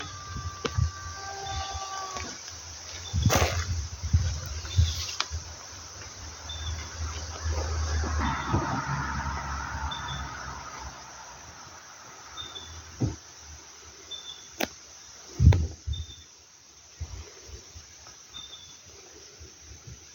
Spotted Pardalote (Pardalotus punctatus)
Location or protected area: Royal National Park
Detailed location: Wattamola
Condition: Wild
Certainty: Recorded vocal
spotted-pardalote-royal-park.mp3